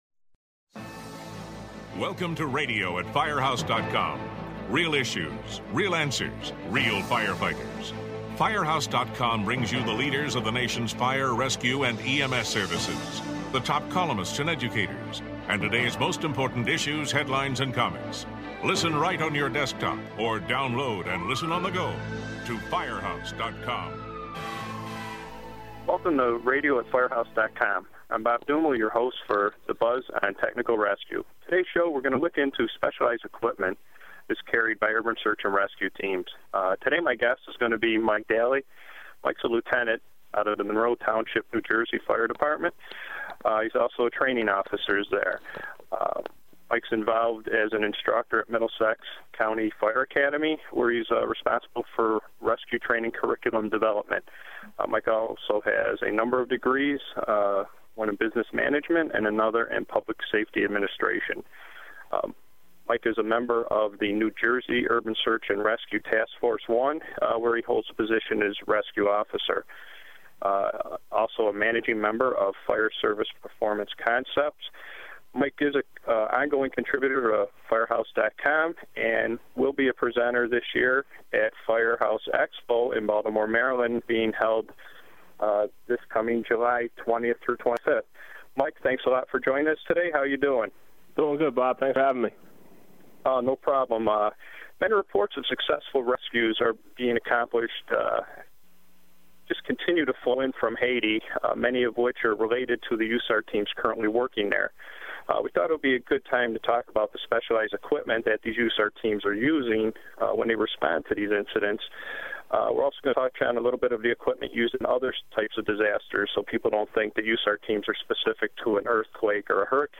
The two discuss the different types of search gear, breaking and breaching tools and shoring equipment. Hear how they transport the equipment from their base to the scene of a catastrophe and what they need to bring to be self-sufficient for the first 72 hours.